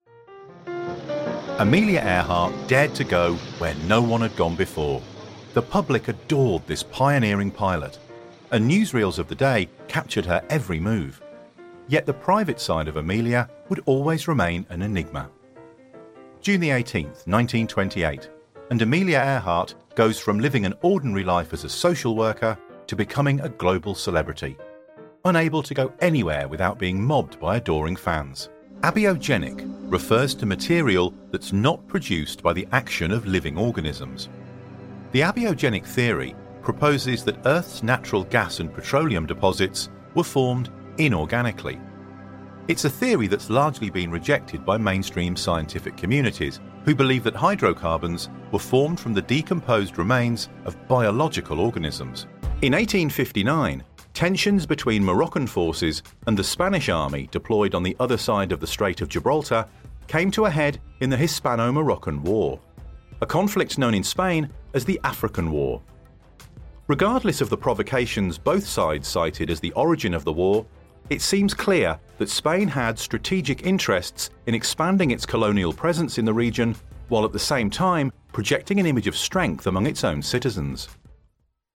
Male
British English (Native)
Assured, Authoritative, Confident, Corporate, Engaging, Friendly, Natural, Smooth, Deep, Versatile, Warm
E LEARNING DEMO.mp3
Microphone: Shure SM7B
Audio equipment: PRE-AMP: dbx 286s. AUDIO INTERFACE: Focusrite Scarlett Solo.